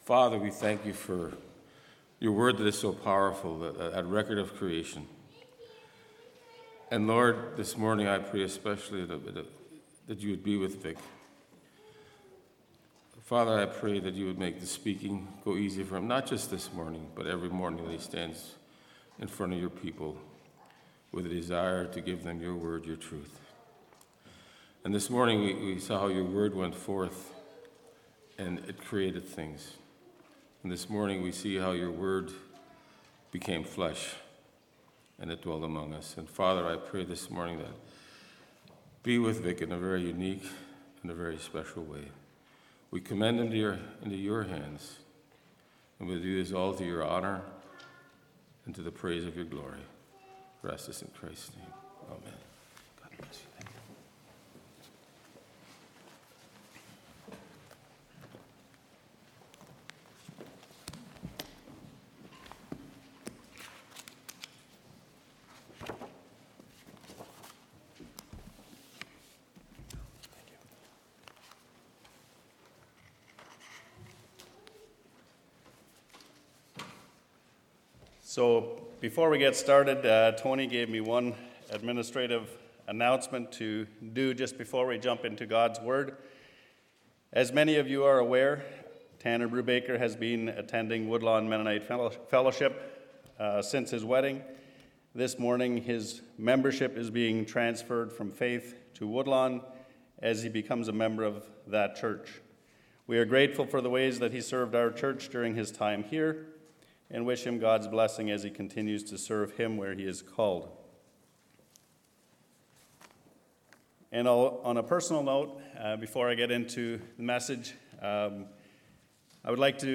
Sermons/Media | Faith Mennonite Church